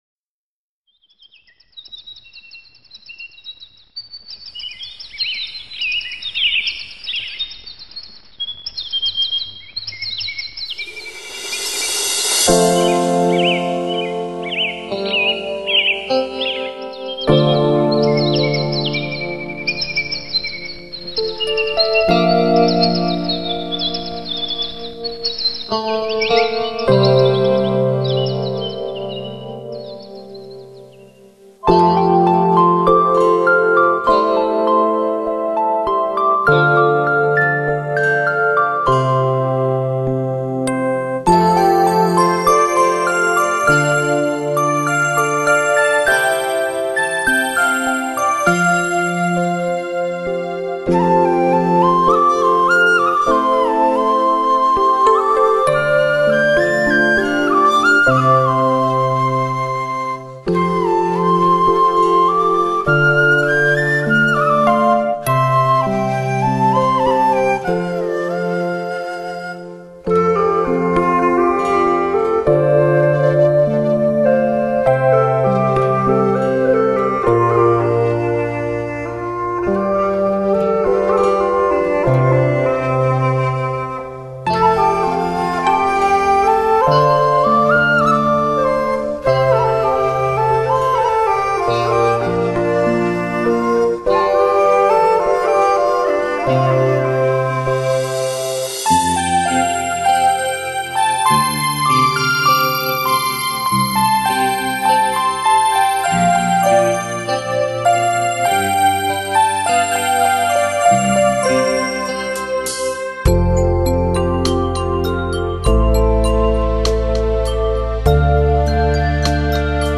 葫芦丝演奏